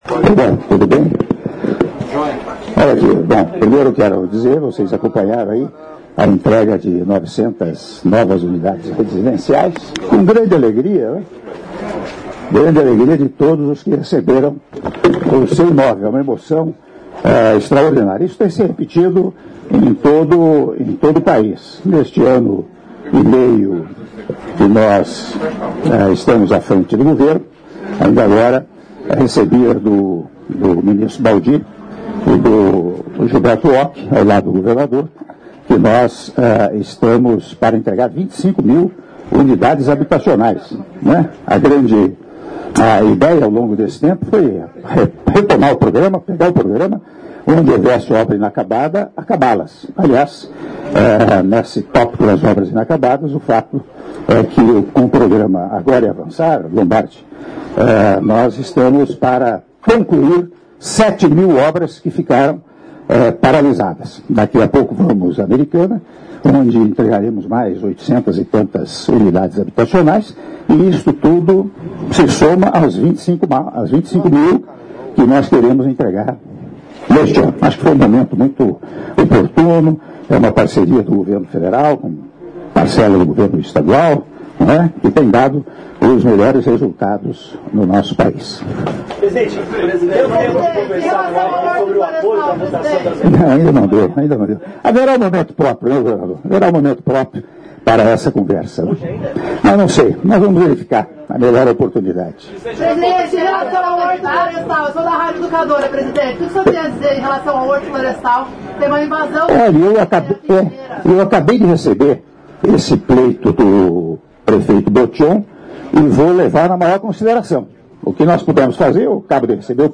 Áudio da entrevista coletiva concedida pelo Presidente da República, Michel Temer, após Cerimônia de Entrega de 900 UH do Condomínio Residencial Rubi III a V do Programa Minha Casa Minha Vida - (05min09s) - Limeira/SP — Biblioteca